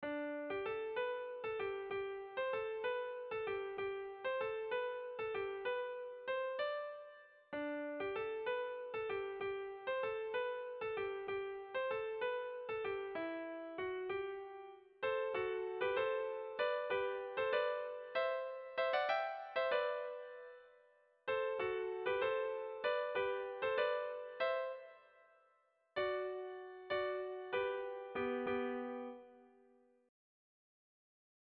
Irrizkoa
Zuberoa < Euskal Herria
Lauko handia (hg) / Bi puntuko handia (ip)
A1A2